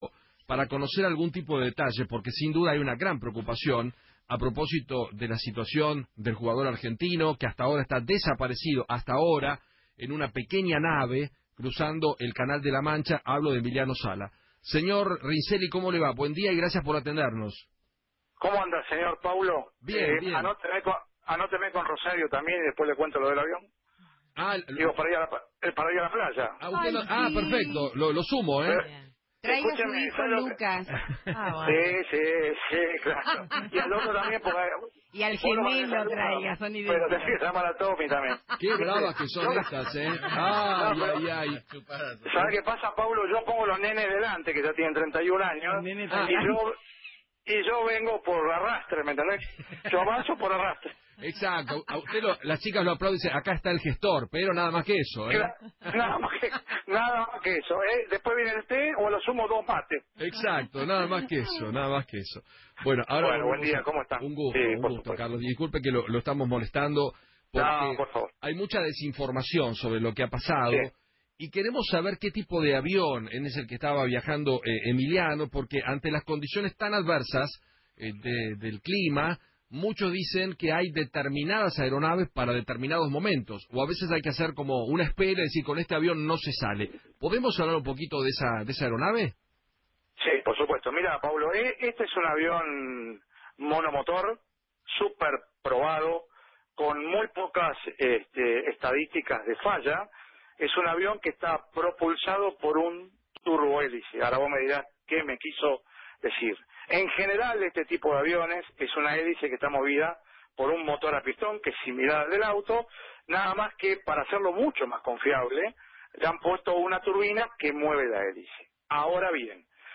analista aeronáutico, habló en Feinmann 910 y contó que “Era un avión monomotor